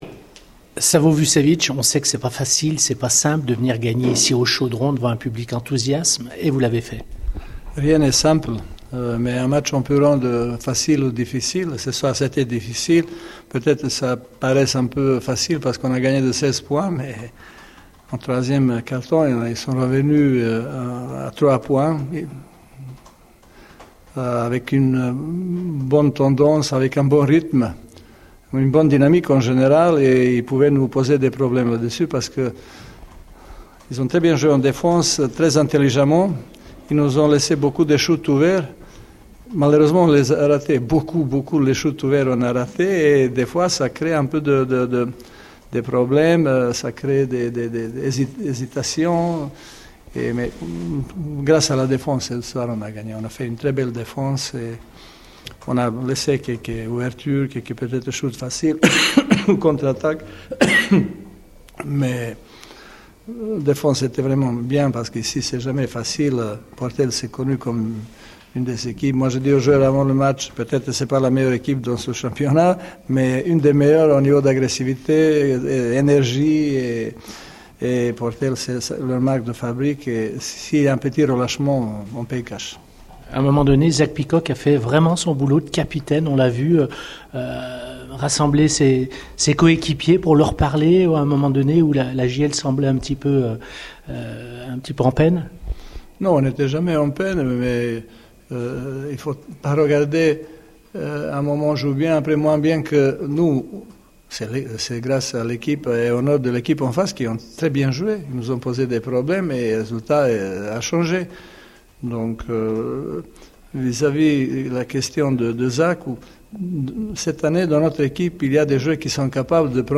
LES RÉACTIONS